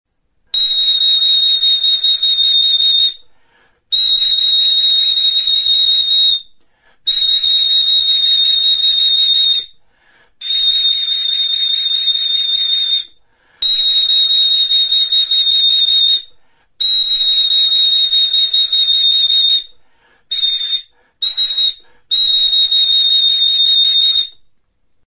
TXULUBITA (Zulo batekin) | Soinuenea Herri Musikaren Txokoa
Enregistré avec cet instrument de musique.
Lizar makilarekin egindako txulubita da. Goikaldea azal tutua da, muturrean moko flauta gisako ahokoa du, eta tonu aldaketarako zulo bat dauka.